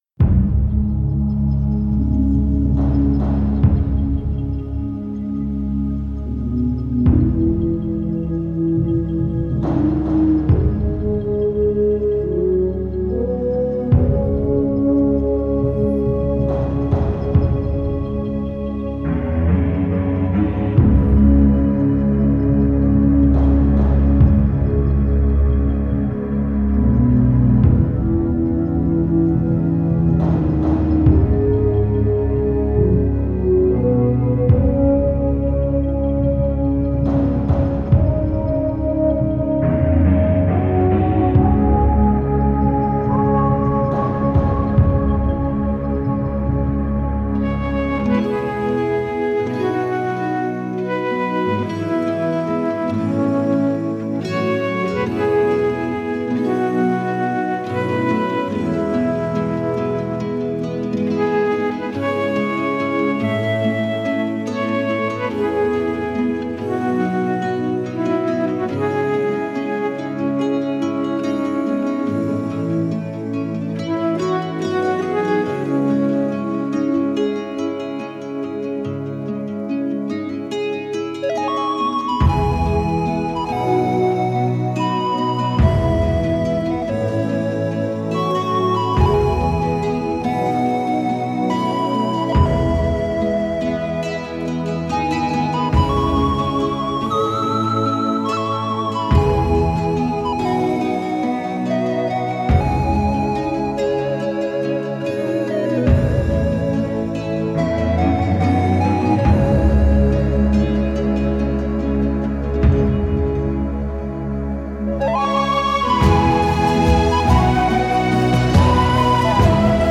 New age Нью эйдж Музыка new age